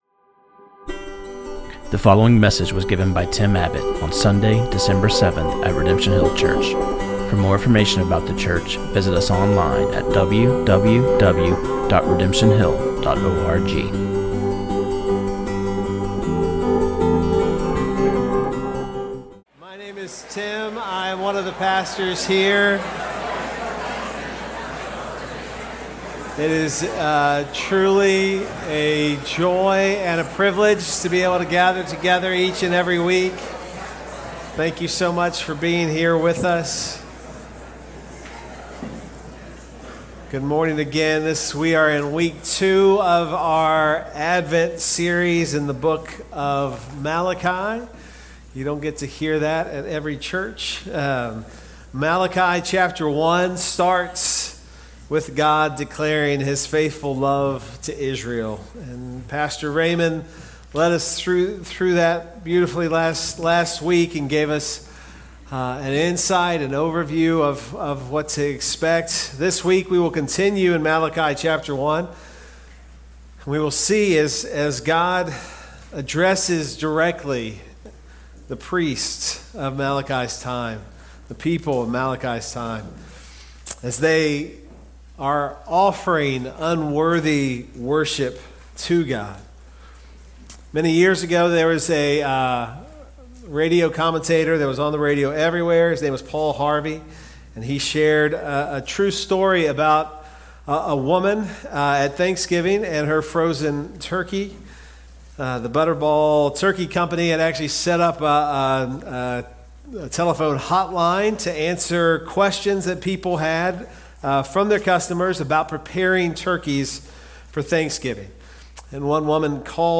Give Online Prayer Request This sermon on Malachi 1:6-2:9